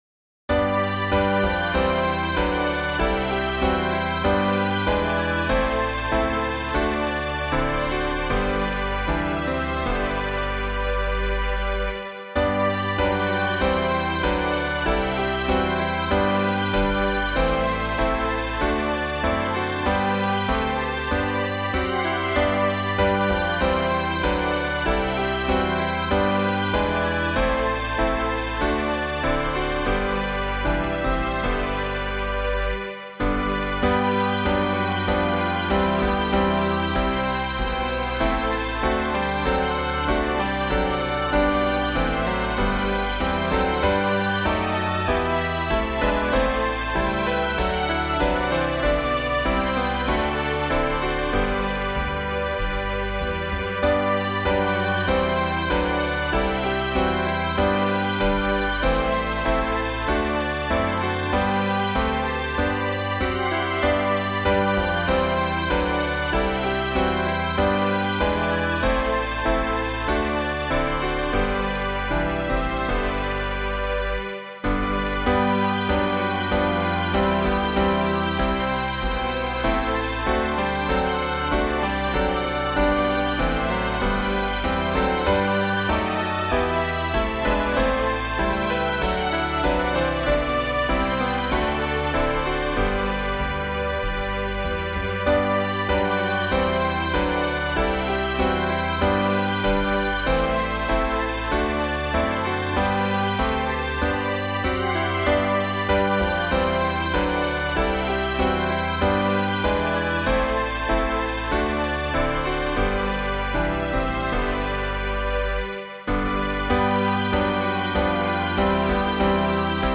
伴奏
示唱
本诗的曲调原为英国17世纪一首对国王查理二世表示效忠的舞曲，后经马丁.肖改编，与本诗相配。